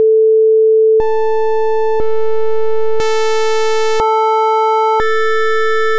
Further OPL-inspired variations
Here they are again, 1 second each and reusing the same oscillator.